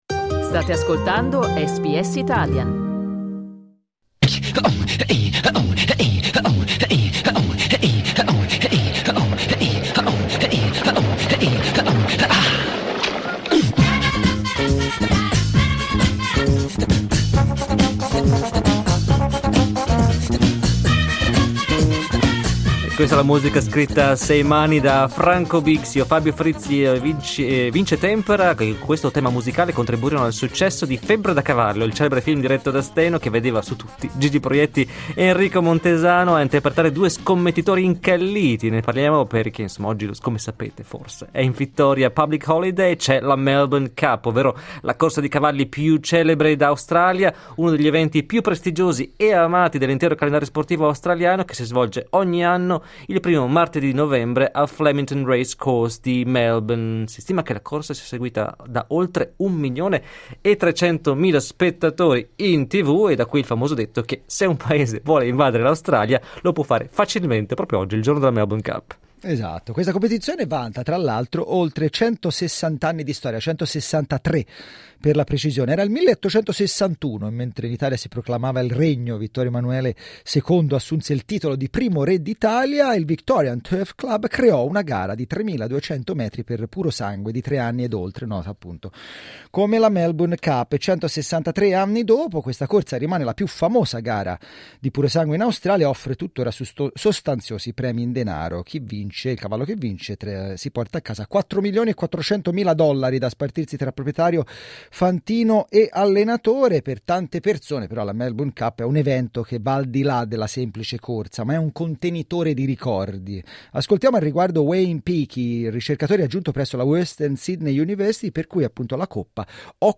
Source: AAP In questo servizio presentiamo il parere di addetti ai lavori sugli aspetti più problematici, ossia quelli che legano questo evento ormai storico con il gioco d'azzardo e con il benessere degli animali.